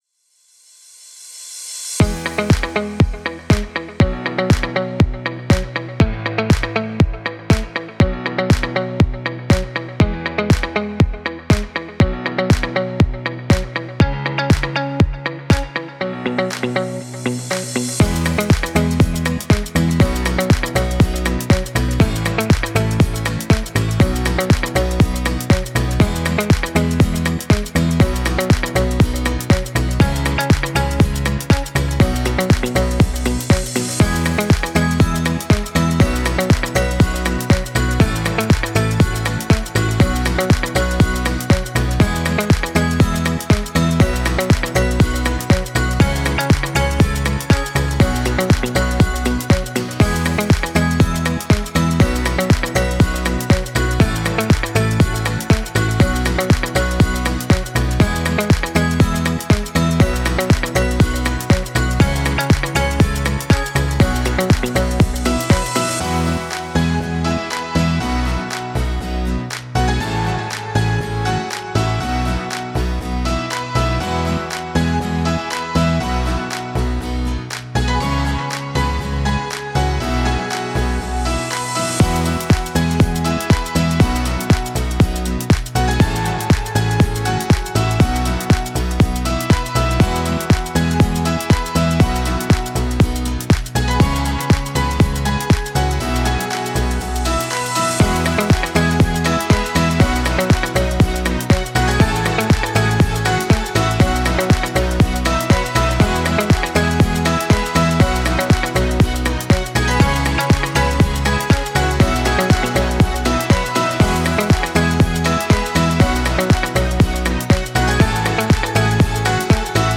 Genre: corporate, pop.